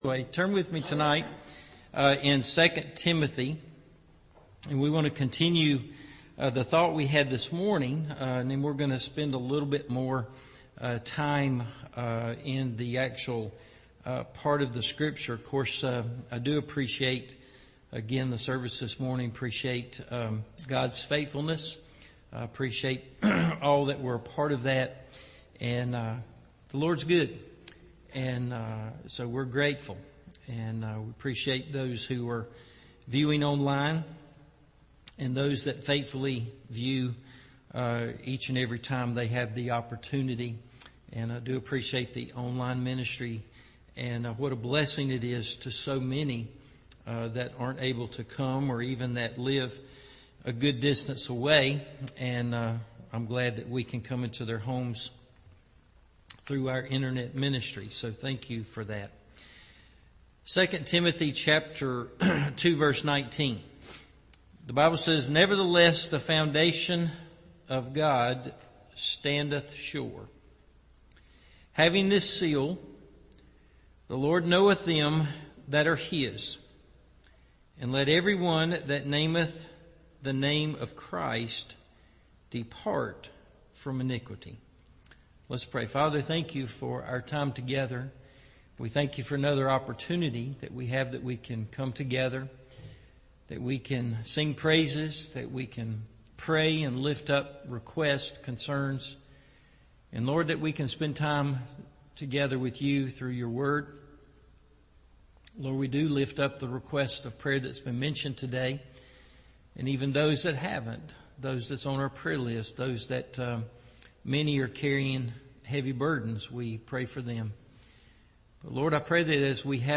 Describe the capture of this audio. The Foundation of God Standeth Sure Part 2 – Evening Service